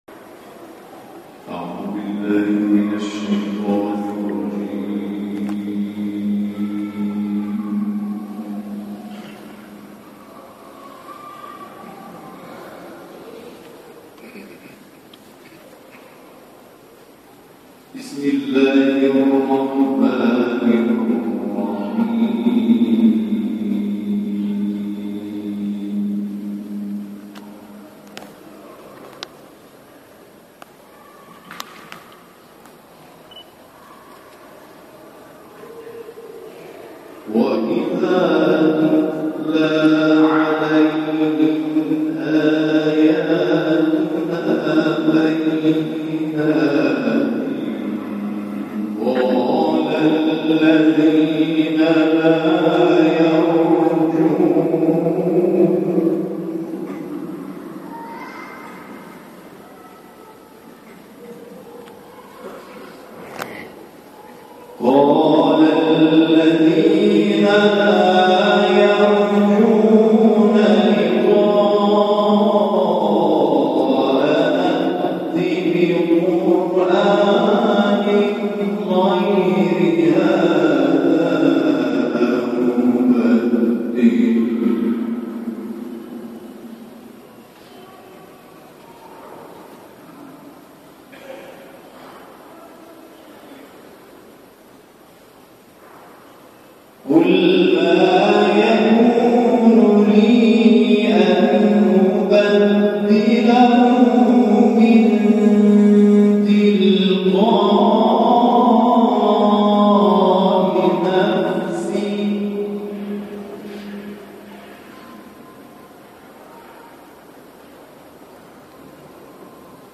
گروه بین‌الملل: مرحله فینال مسابقات قرائت قرآن ترکیه امروز، پنجشنبه 27 خردادماه برگزار شد.